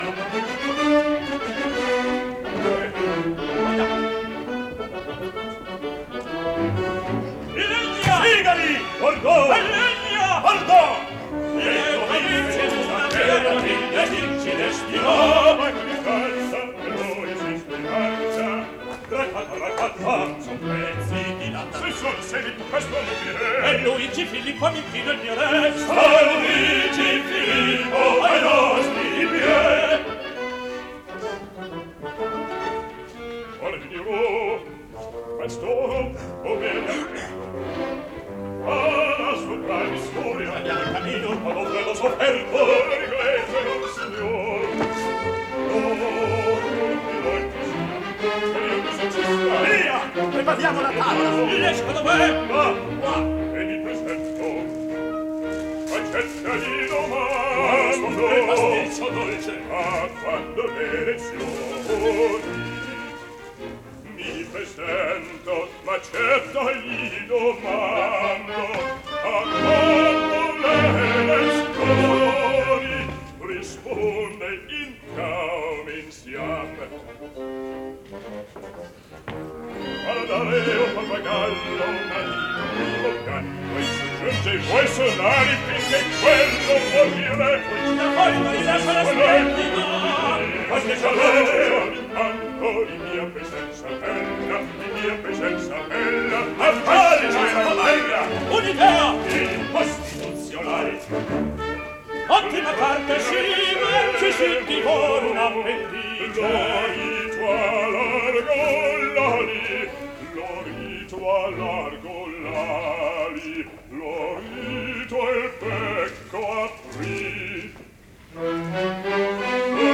Live performance recorded February 15, 1958
Orchestra and Chorus